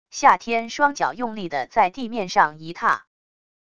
夏天双脚用力的在地面上一踏wav音频